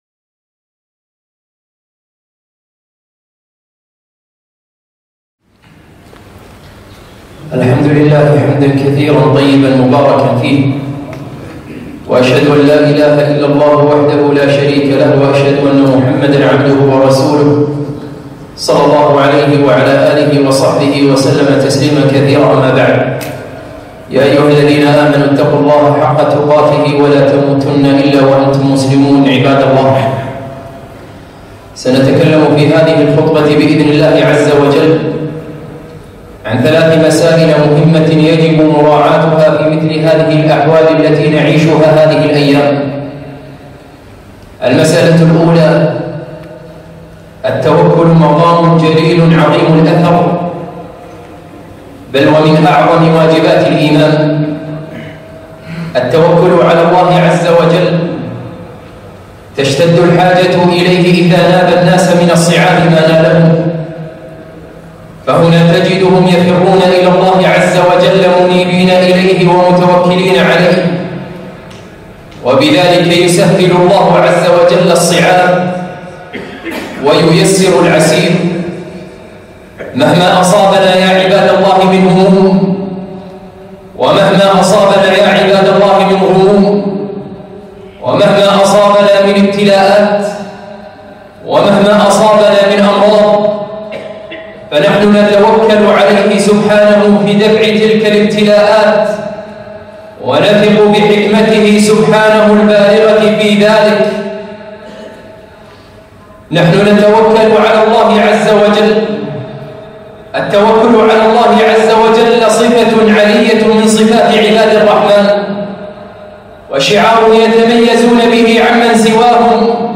خطبة - ثلاث وقفات مع انتشار الوباء